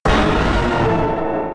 Cri de Méga-Dracaufeu Y K.O. dans Pokémon X et Y.